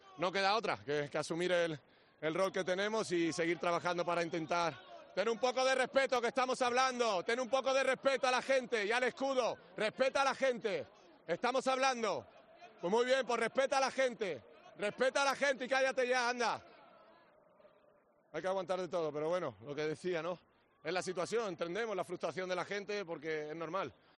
Esta tensión se escenificó durante la entrevista tras el partido en la que Sergio Ramos estaba explicando cómo se encontraba el equipo con la derrota. En mitad de sus palabras, se dirigió hacia la grada para increpar a un aficionado que le estaba gritando: "Pon un poco de respeto a la gente y al escudo, que estamos hablando. Respeta a la gente y cállate ya".